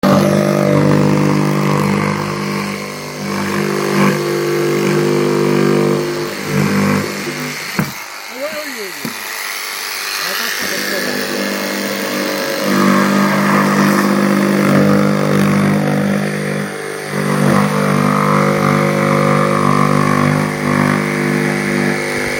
Yksityisyys-, supersankarius-, ja muista tekosyistä johtuen emme voi jakaa videomateriaalia, mutta tällä kertaa olemme ripotelleet raporttimme joukkoon ääninäytteitä, joitten avulla ja mielikuvituksenne voimalla voitte saada etäisen vaikutelman töistämme.
Kuten tarkkakorvaisimmat varmasti huomasivatkin, Audiossa 2 on kyse täysin eri työvaiheesta kuin Audiossa 1.
Jos tunnistit korvakuuntelulla virheettömästi työvaiheet ja käytetyt työkalut, ja osaat lisätehtävänä ympyröidä yllä olevasta kuvasta paarmakoiraat, lähetä vastauksesi meille.